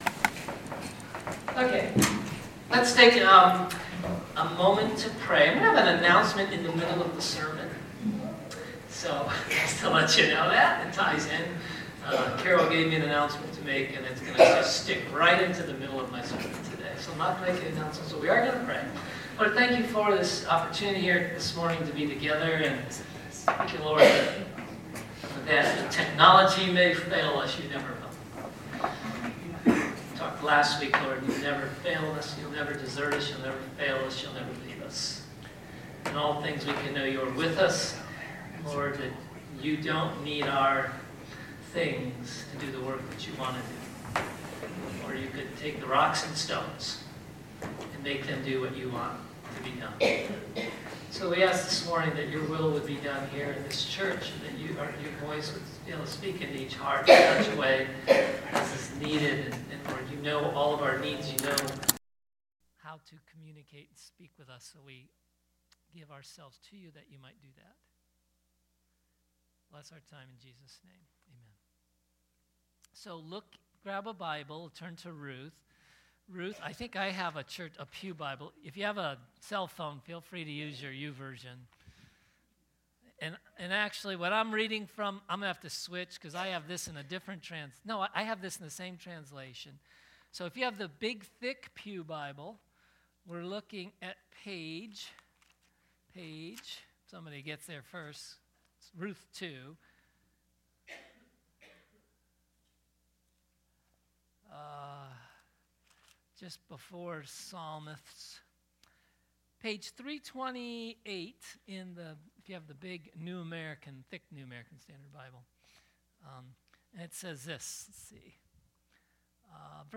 Grace Summit Community Church | Cuyahoga Falls, Ohio